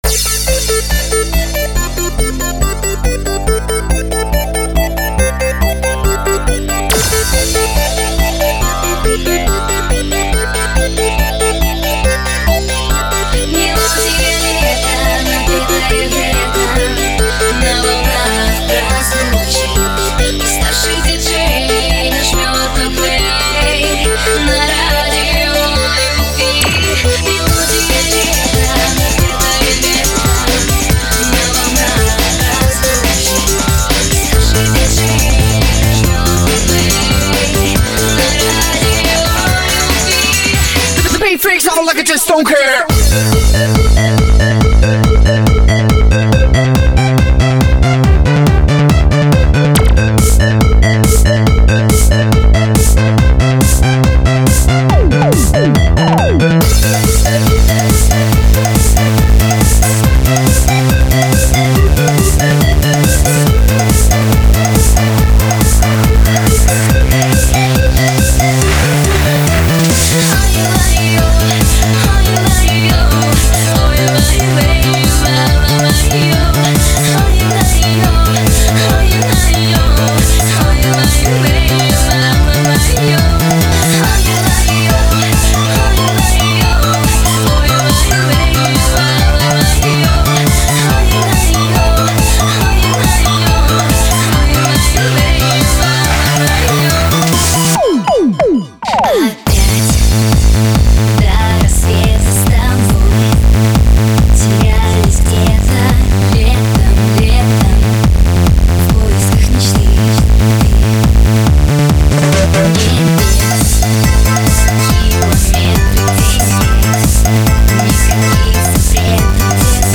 (Ремикс) PR